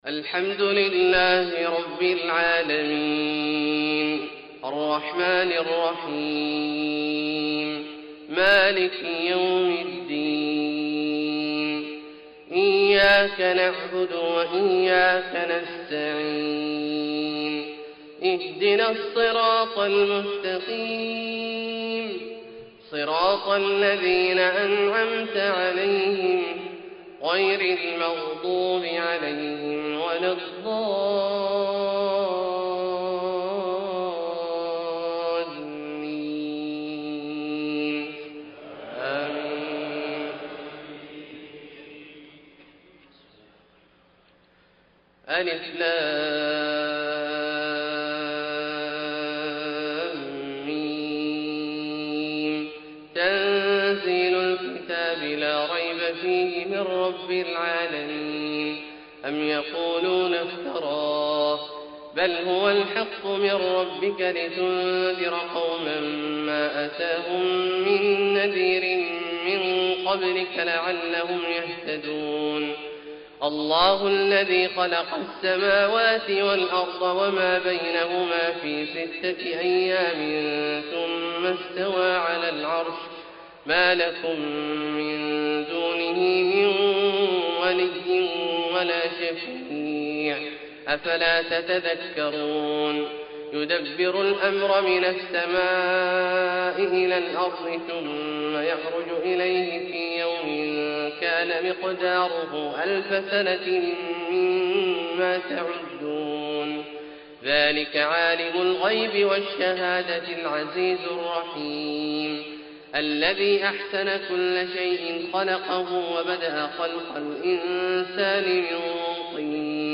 فجر 2-4-1433هـ سورتي السجدة والإنسان > ١٤٣٣ هـ > الفروض - تلاوات عبدالله الجهني